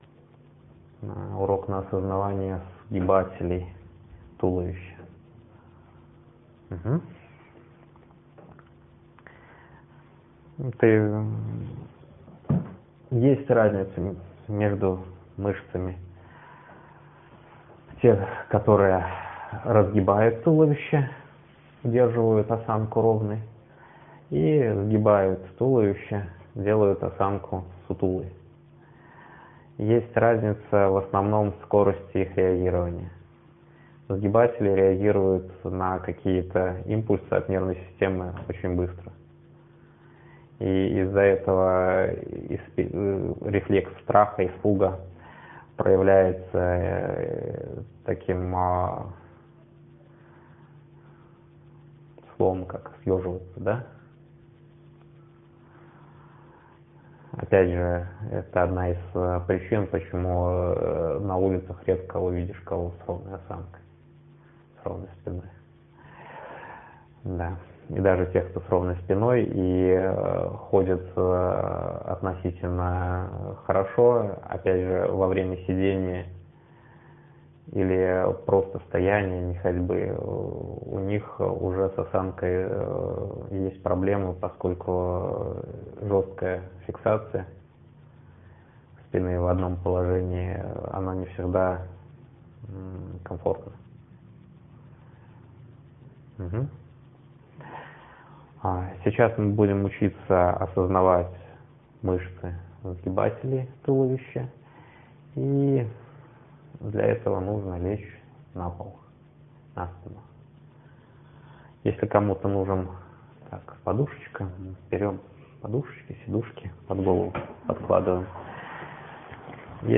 Ниже на странице расположена ссылка на скачивание аудиоурока в хорошем качестве на осознавание мышц, участвующих в рефлексе "красного света"
02_01_осознавание_сгибателей_туловища_0.mp3